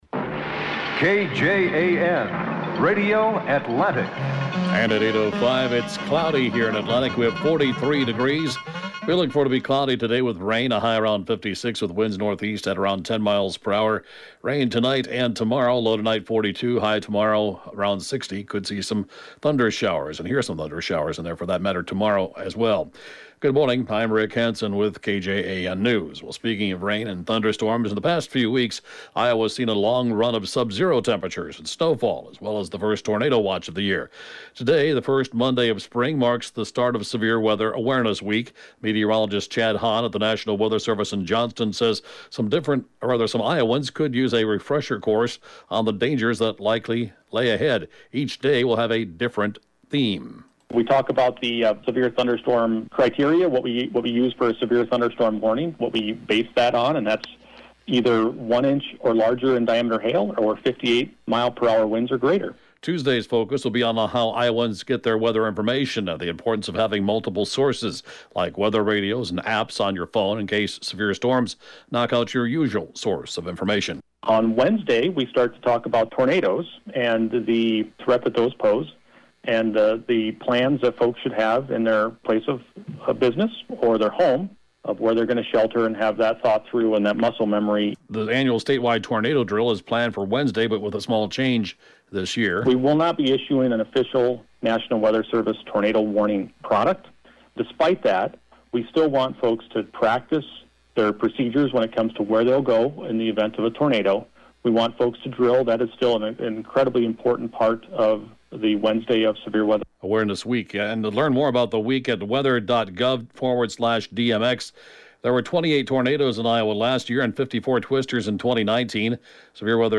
KJAN News can be heard at five minutes after every hour right after Fox News 24 hours a day!